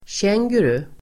Ladda ner uttalet
Uttal: [tj'eng:guru]